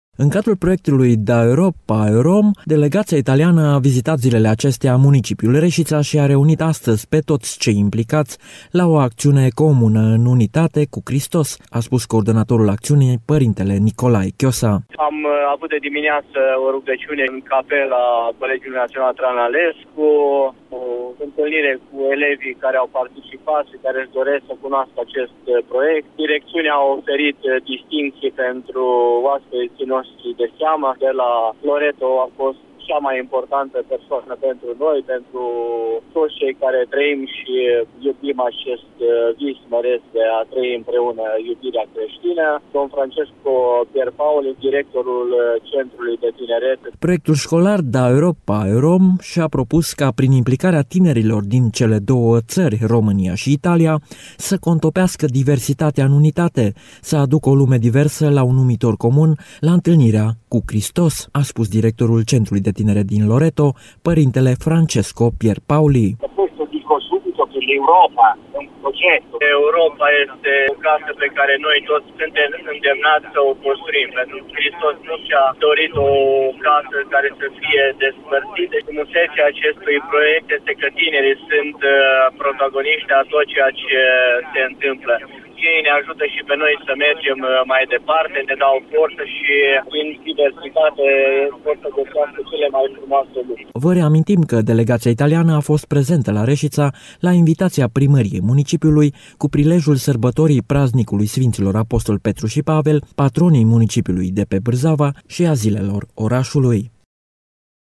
În capela Colegiului Național ,,Traian Lalescu” din Reșița, a avut loc astăzi o întâlnire ecumenică, care a culminat cu un moment de rugăciune comună pentru unitatea creştinilor.